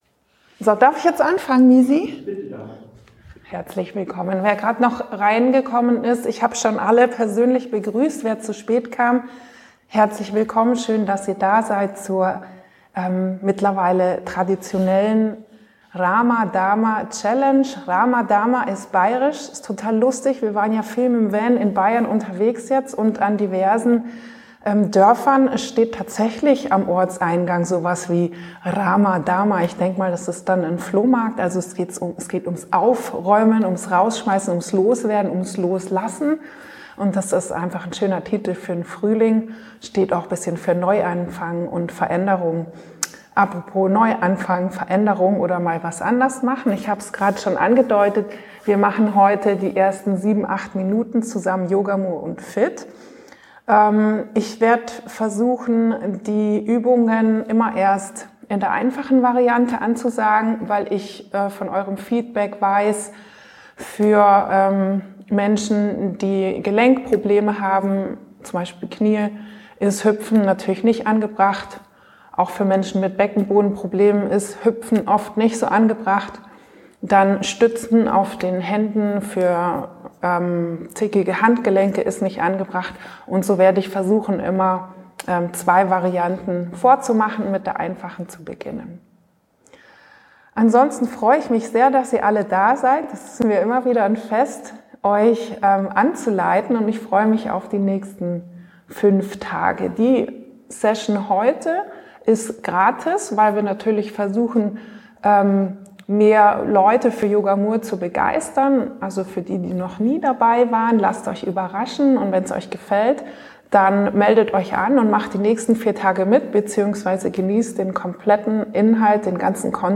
Live-Challenge Rama Dama 4: Welcome-Yogasession.
Welcome-Yogasession